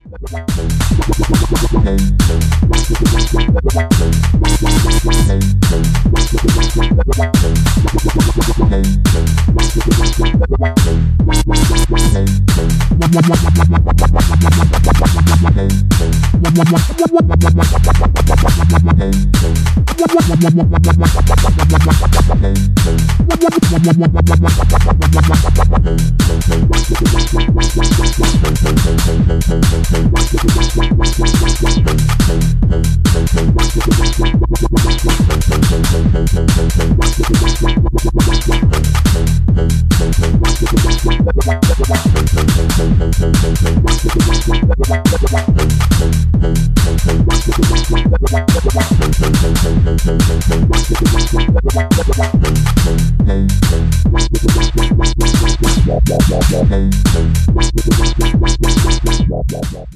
141 bpm